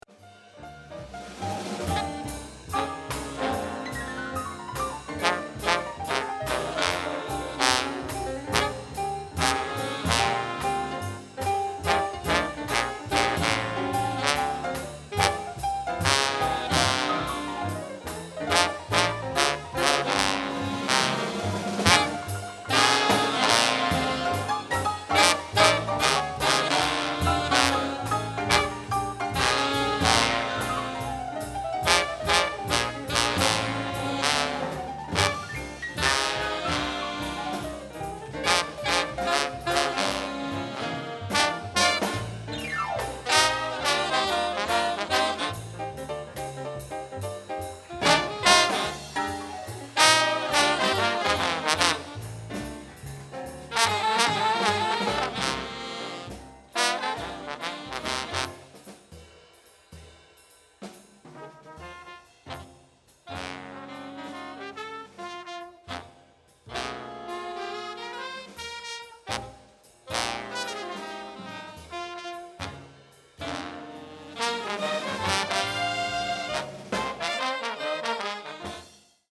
Quartetto di sassofoni